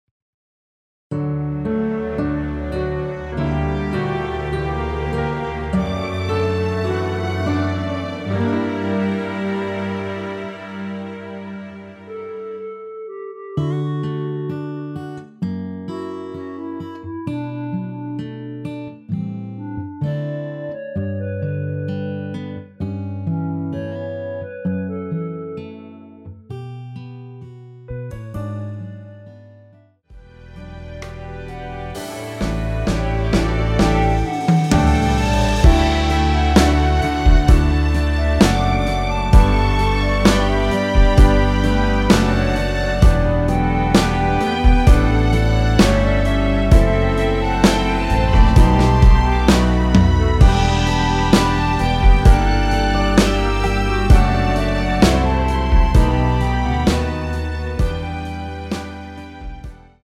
원키 멜로디 포함된 MR입니다.(미리듣기 확인)
D
앞부분30초, 뒷부분30초씩 편집해서 올려 드리고 있습니다.
중간에 음이 끈어지고 다시 나오는 이유는